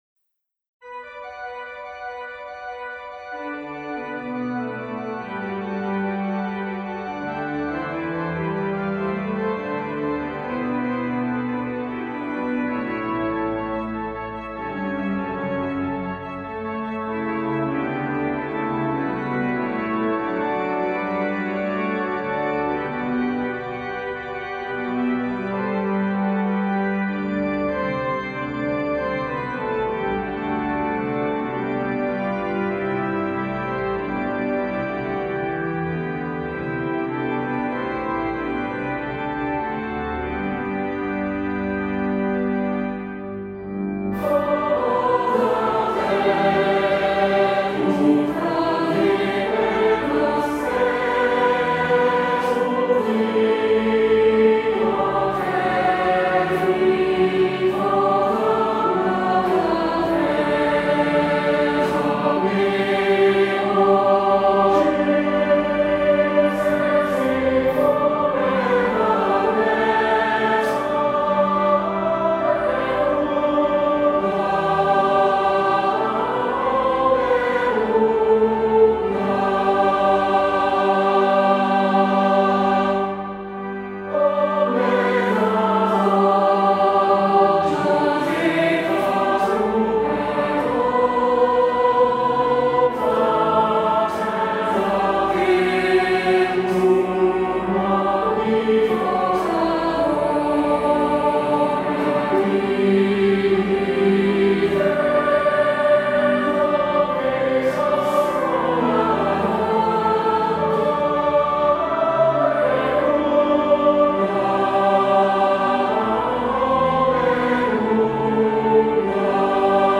Introduction, alternate harmonizations, and descant
Organ prologue, three harmonizations, and descant